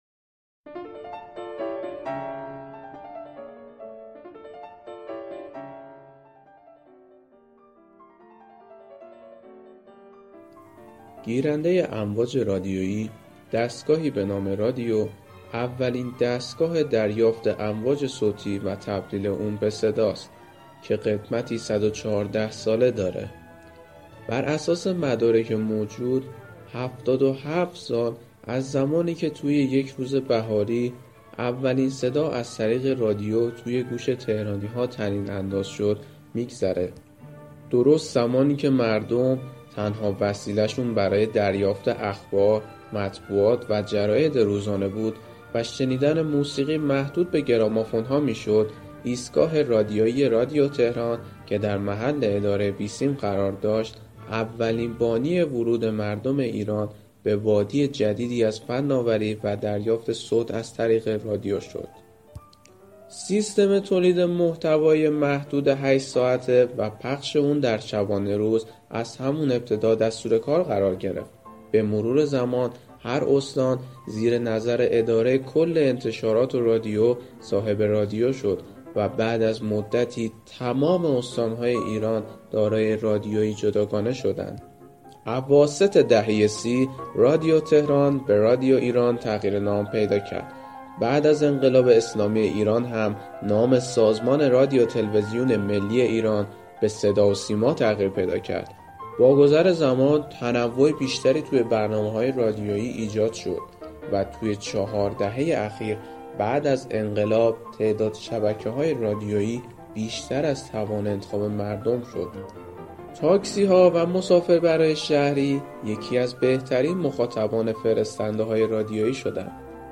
قطعه موسیقی اثر موتزارت